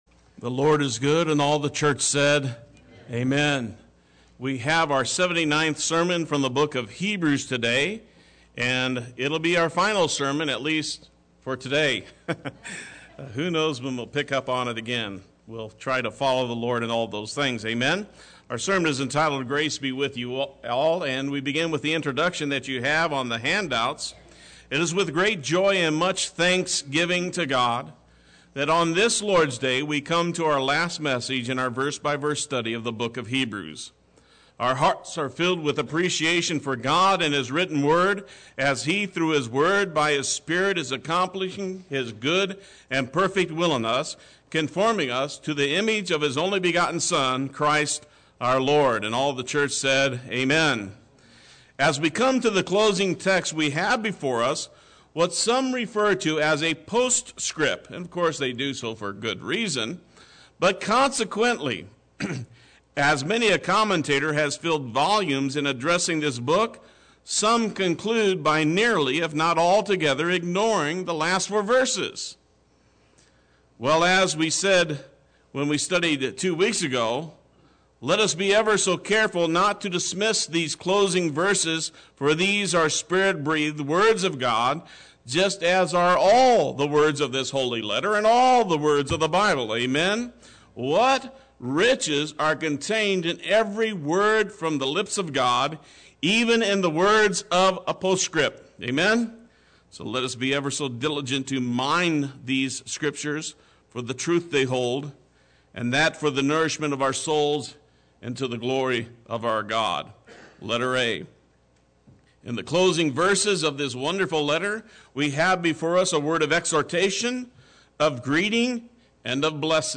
Play Sermon Get HCF Teaching Automatically.
Grace Be With You All Sunday Worship